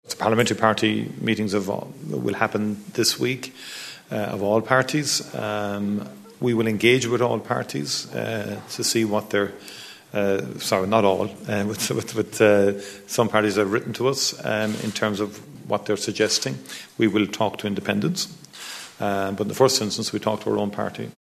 Micheál Martin has refused to say whether he would prefer to form a coalition with smaller, left-leaning parties or with independents: